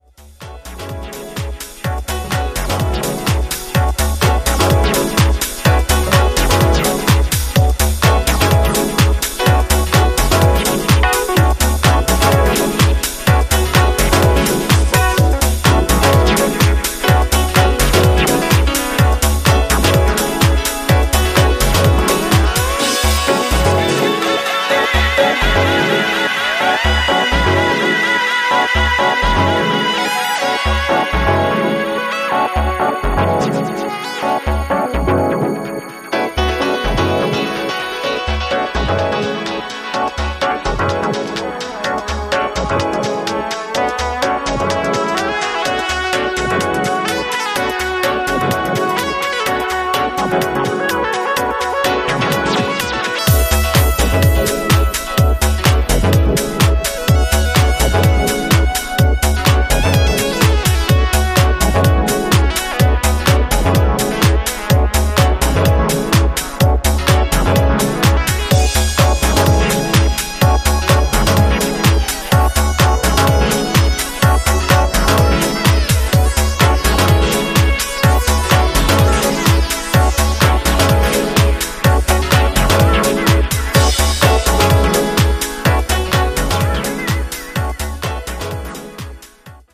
ピアノやスペーシーなリードを軸に極彩色のテクスチャーを組んだ、実に見事な仕上がりです！